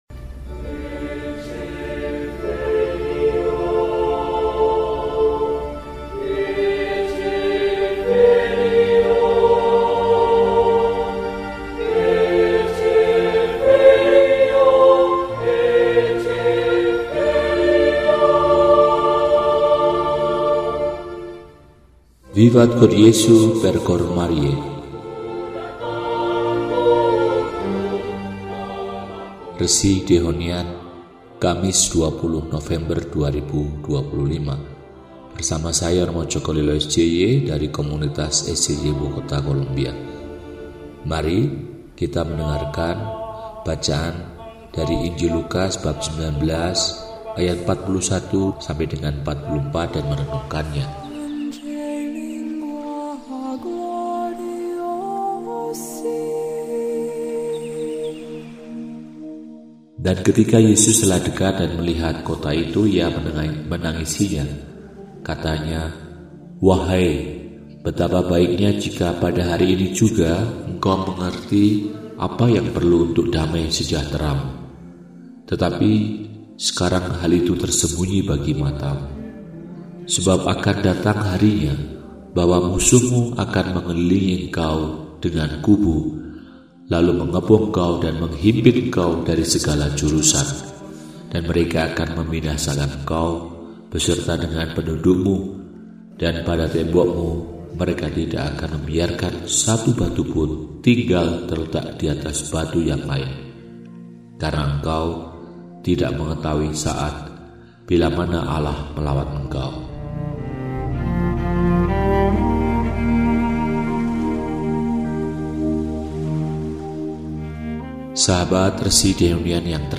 Kamis, 20 November 2025 – Hari Biasa Pekan XXXIII – RESI (Renungan Singkat) DEHONIAN